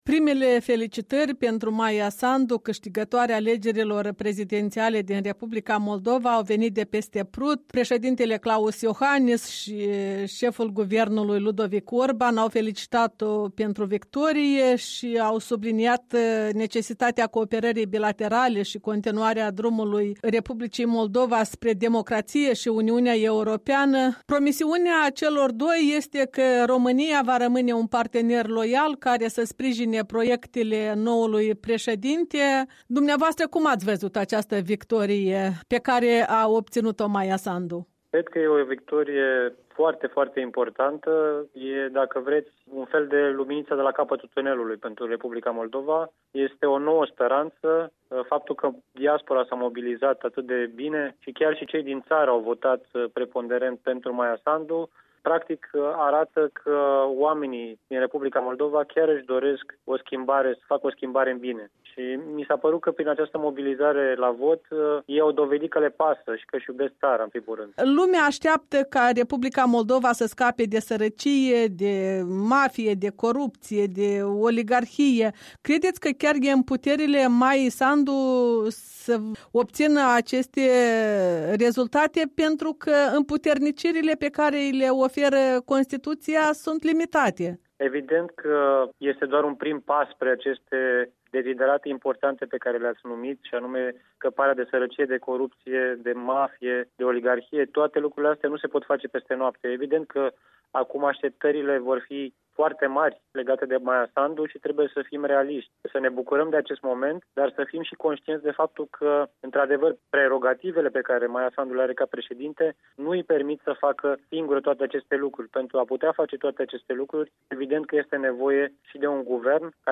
Interviu cu deputatul liberal de la București, Matei Dobrovie